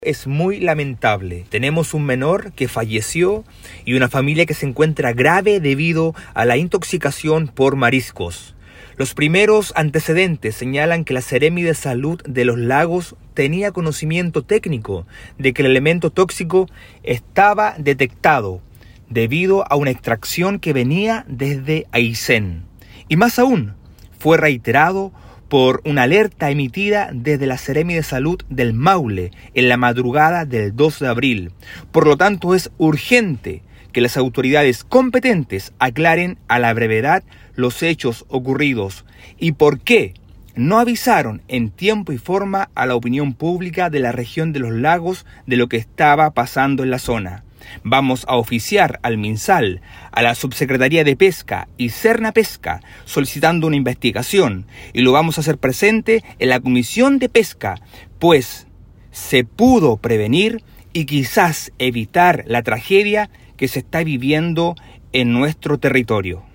El diputado Mauricio González así lo expresó este lunes, señalando que va a pedir una investigación al respecto.